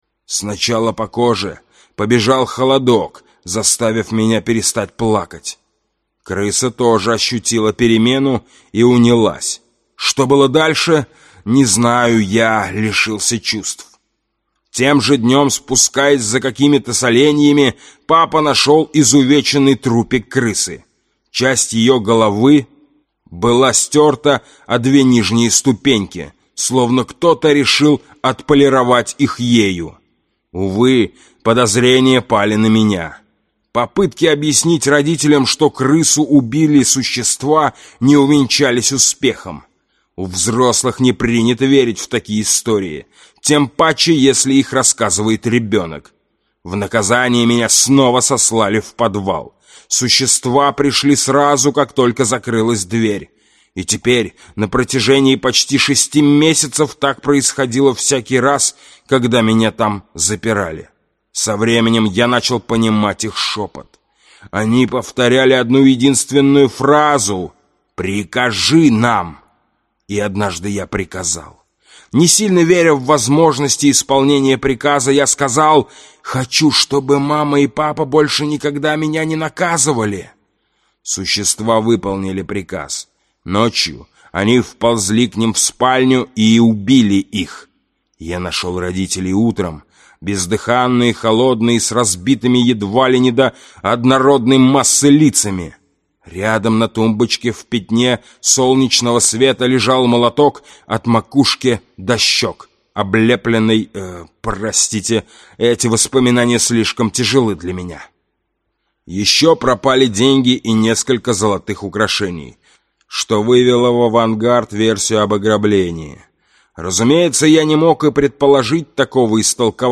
Аудиокнига Окаянное место | Библиотека аудиокниг